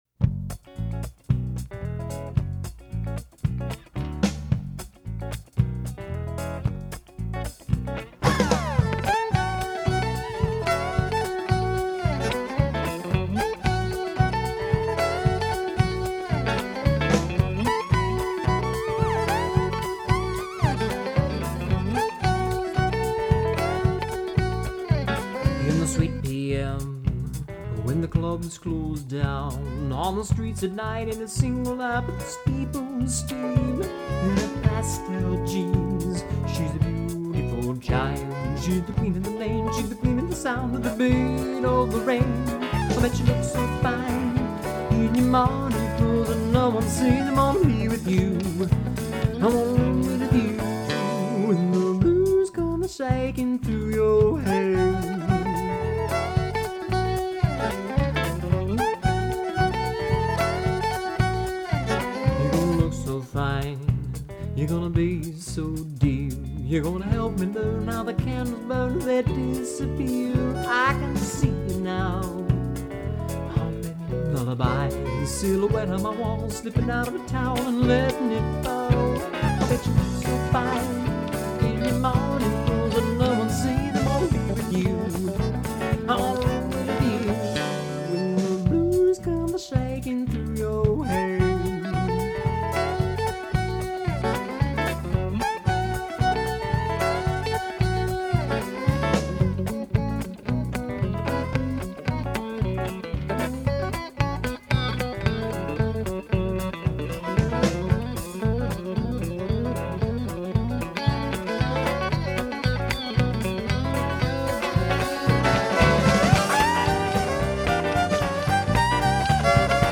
dance band
fiddle and lap steel
guitar and vocals
bass
drums